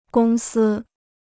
公司 (gōng sī) Free Chinese Dictionary